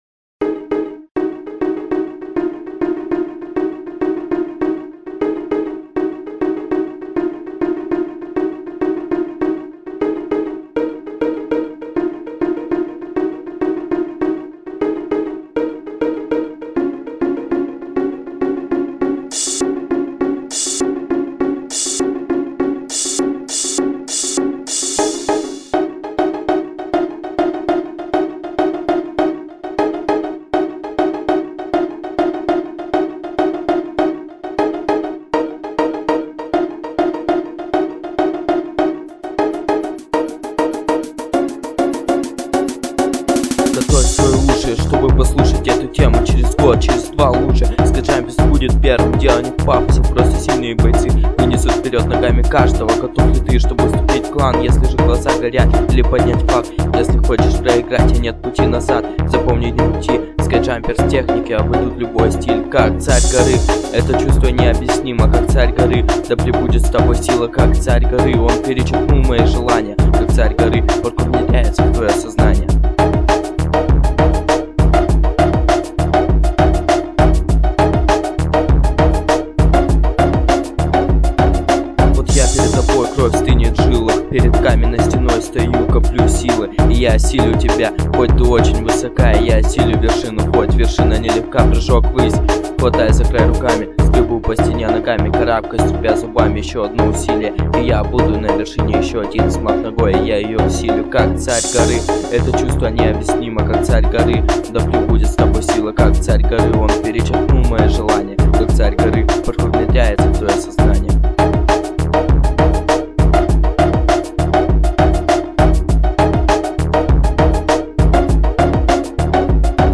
Клубные треки
(Techno mix)